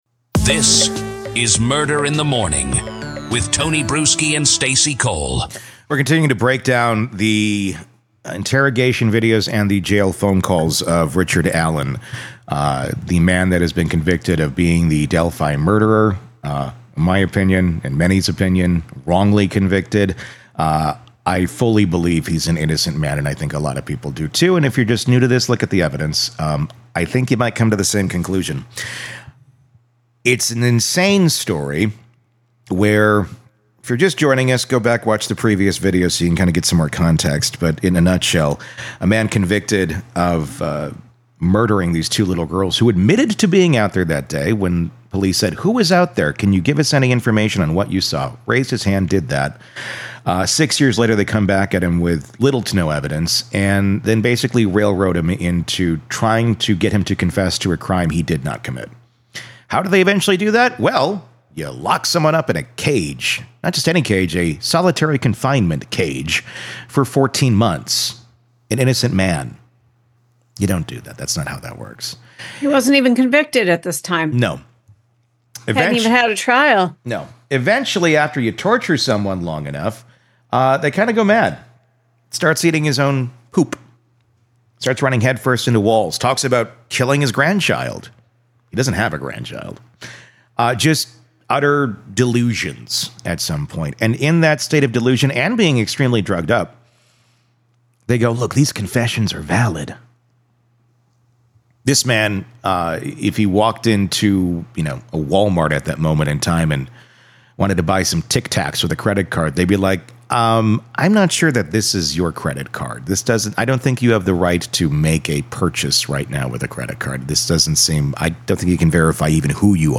And that interrogation, which is now public, begins with Allen trying to stay cool under pressure. He admits he was on the Monon High Bridge the same day the girls vanished.